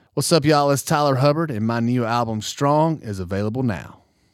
LINER Tyler Hubbard (available now)